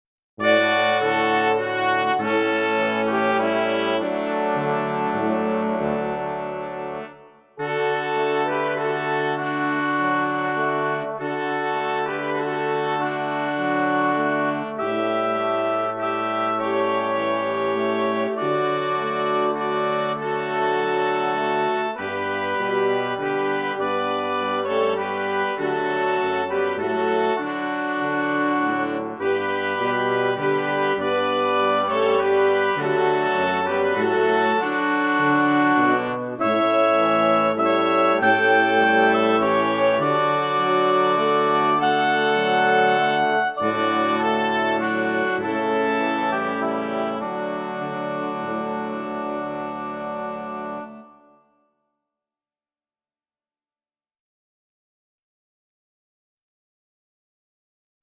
VS Silent Night (backing track)